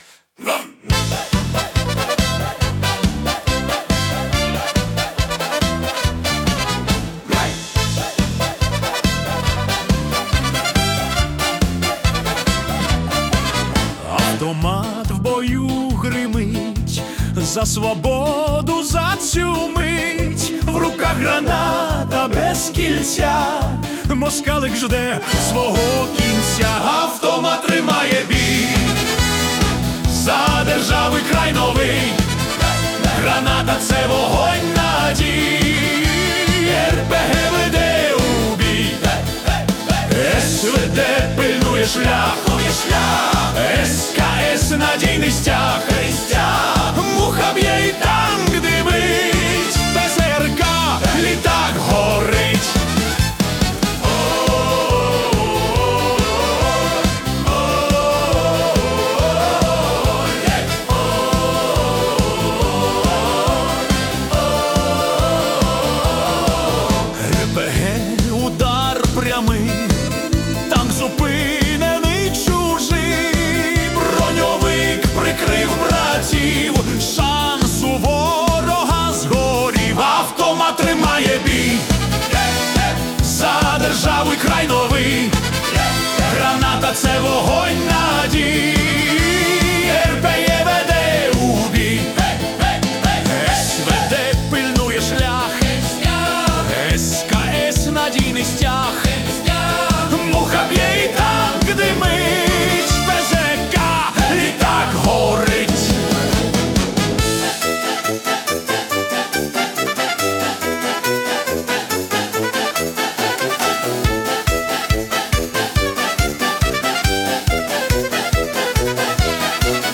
🎵 Жанр: Combat Polka